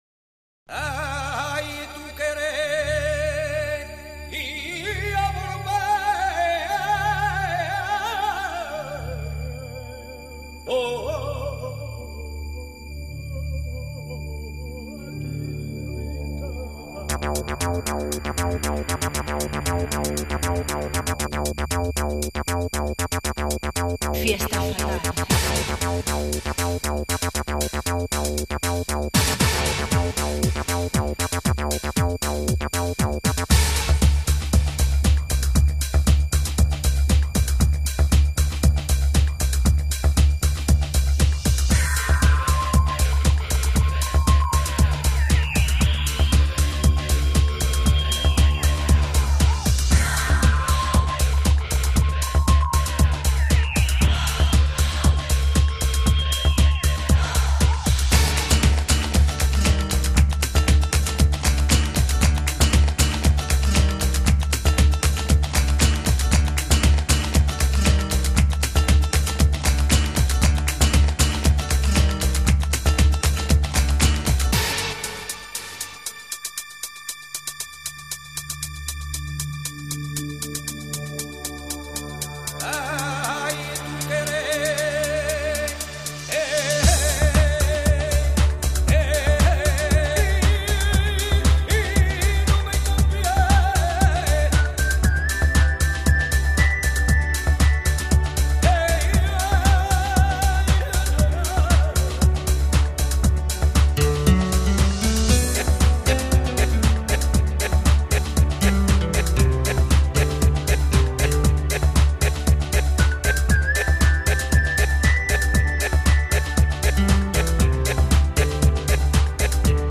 音乐类型：精神元素